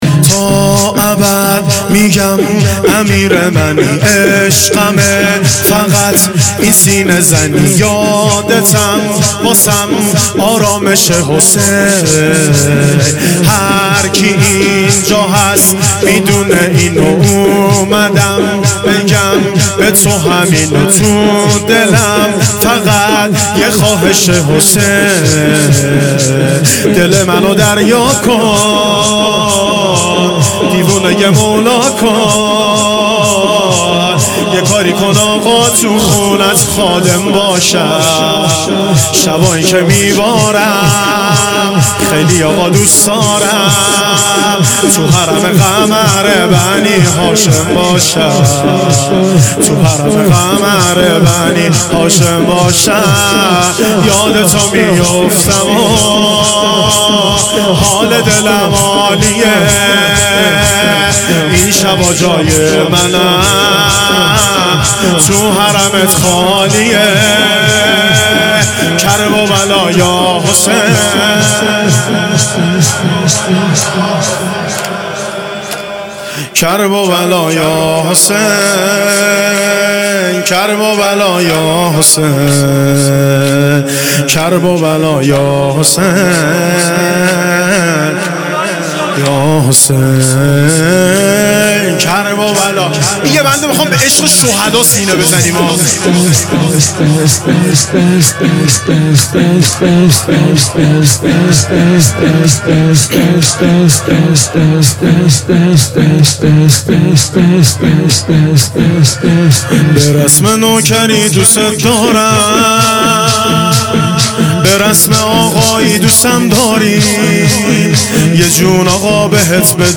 شب هشتم محرم 1400
شور